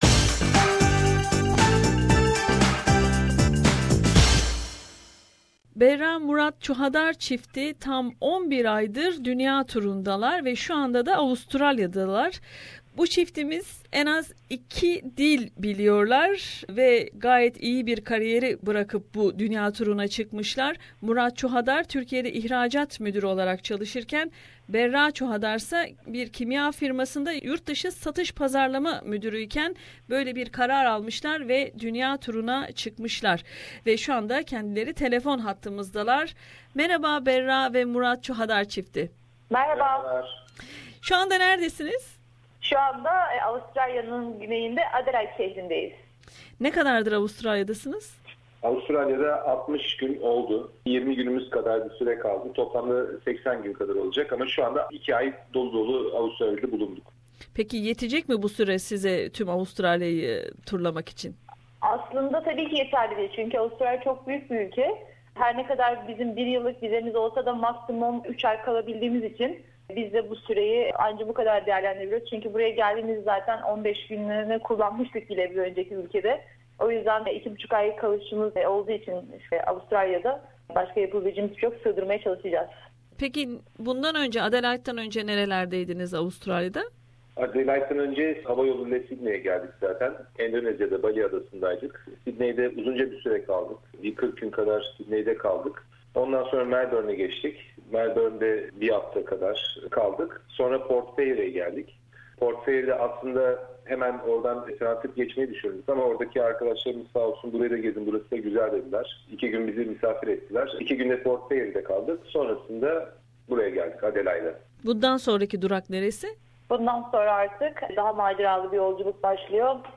Kendileri ile yaptığımız söyleşide hem Avustrala hakkındaki gözlemlerini hem de dünya turuna nasıl karar verdikleri konusunda konuştuk.